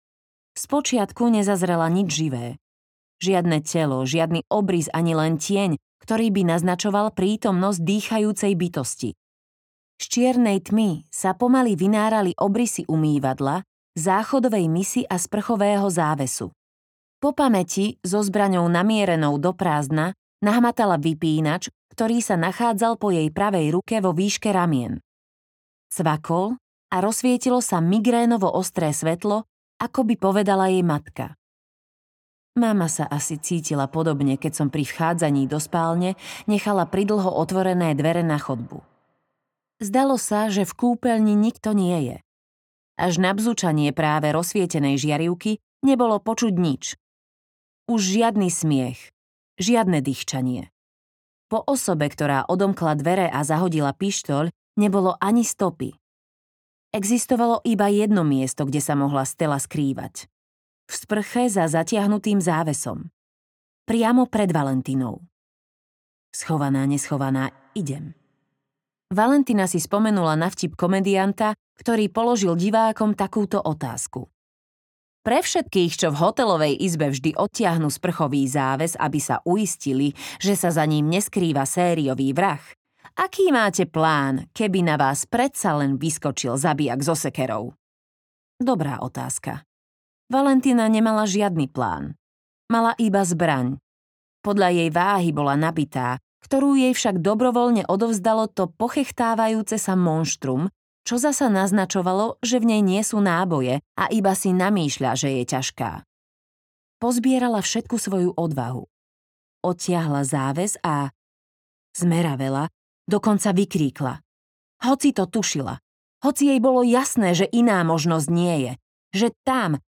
Ukázka z knihy
dievca-z-kalendara-audiokniha